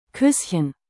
Como aprender a pronunciar Küsschen corretamente?
O segredo está no som da vogal “ü” e na terminação “-chen”, que é muito usada em palavras diminutivas no alemão.
• Já o “-chen” tem um som suave, algo próximo a “rren” em português, mas sem vibração na garganta.
Kusschen.mp3